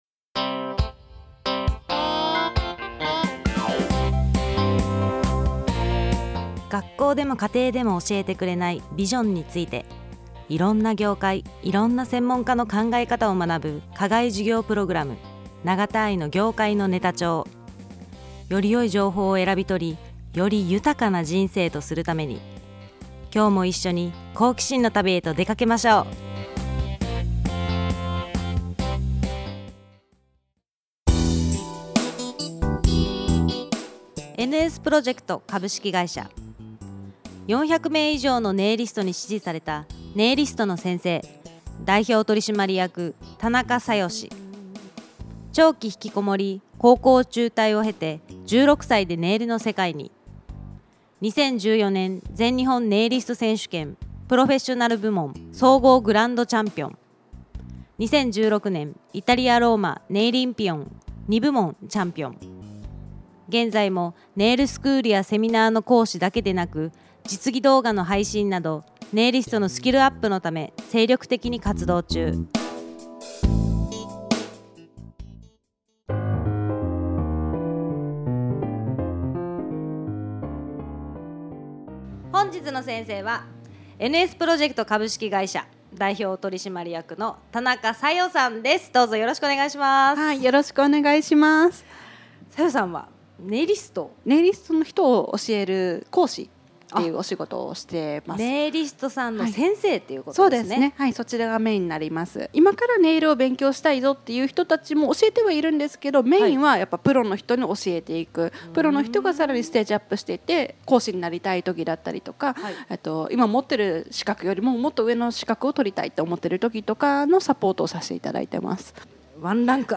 NBCラジオ0115放送分